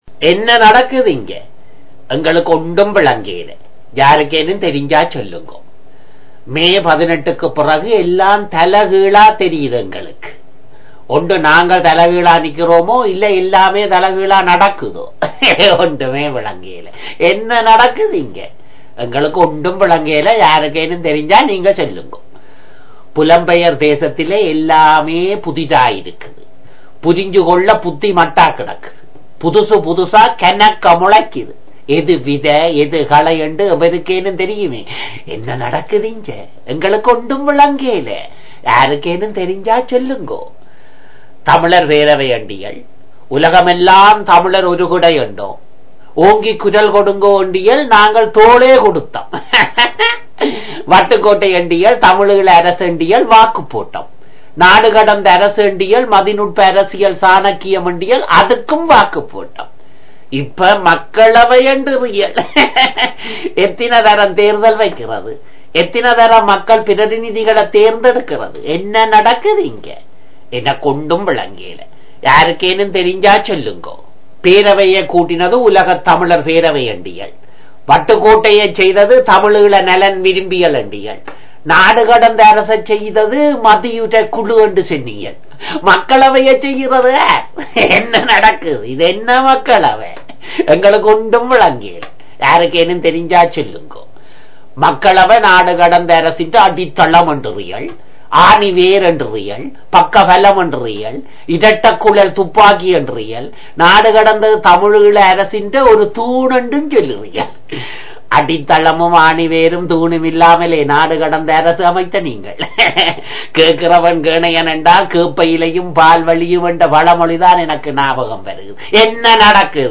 அருமையான குரல் அசைவு.... ஏற்ற...இறக்க...